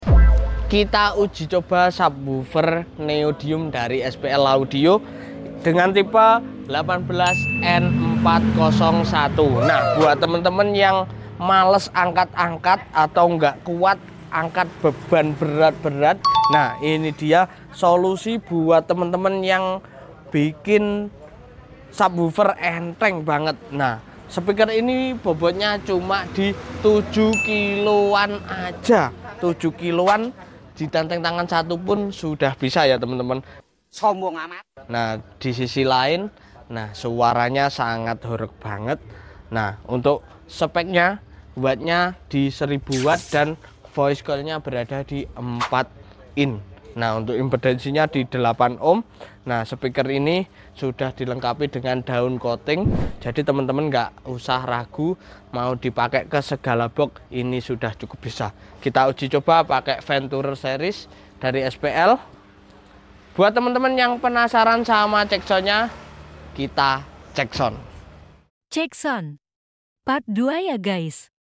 UJI COBAA SPEAKER 18N401 sound effects free download
ENTENG TAPI SUARA NGGAK KALENG- KALENG